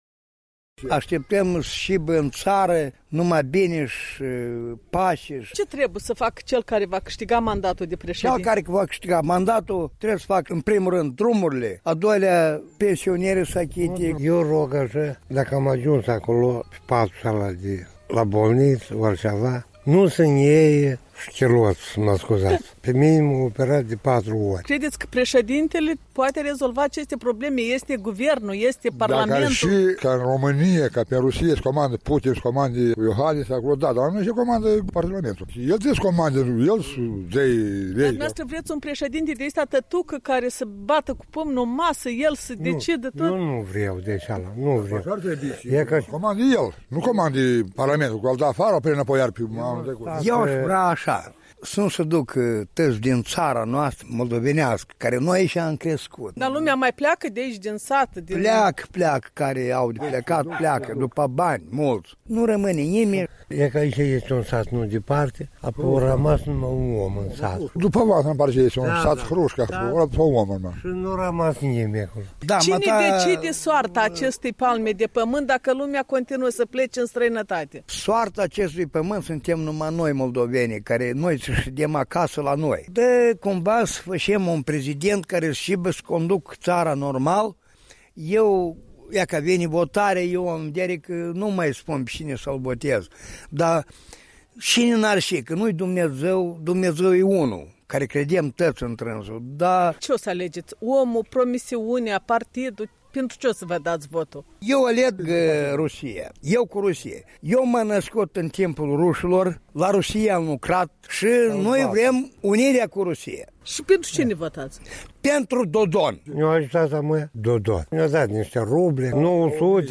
Vox populi: Ce așteptări aveți de la alegerile prezidențiale?